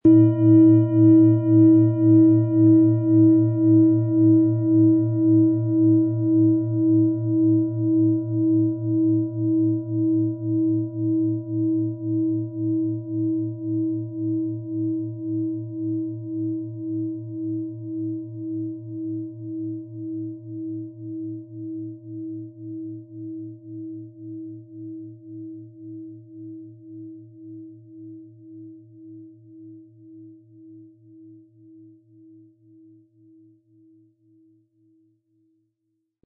Planetenschale® Tiefenentspannung & Umhüllt und geschützt fühlen mit Thetawellen, Ø 19,2 cm, 1000-1100 Gramm inkl. Klöppel
Thetawelle
Diese tibetanische Thetawelle Planetenschale kommt aus einer kleinen und feinen Manufaktur in Indien.
Unter dem Artikel-Bild finden Sie den Original-Klang dieser Schale im Audio-Player - Jetzt reinhören.
Lieferung inklusive passendem Klöppel, der gut zur Klangschale passt und diese sehr schön und wohlklingend ertönen lässt.
MaterialBronze